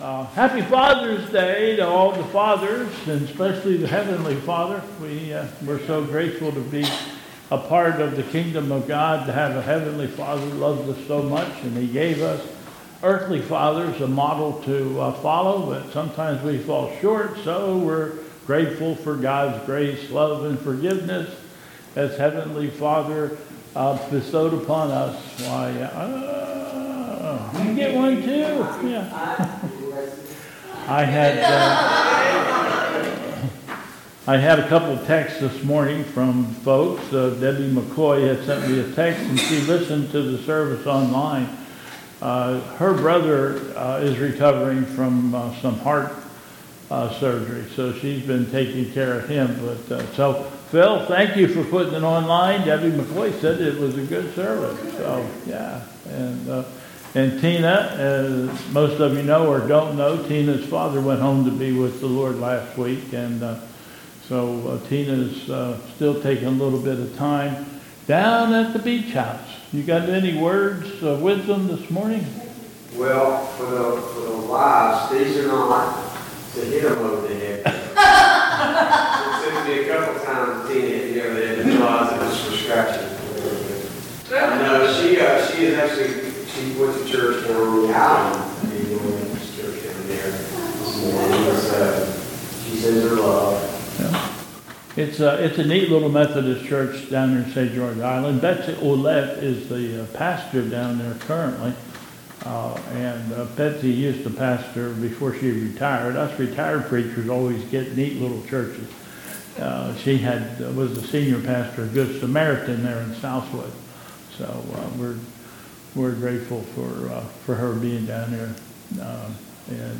2022 Bethel Covid Time Service
Announcements